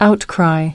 Transcription and pronunciation of the word "outcry" in British and American variants.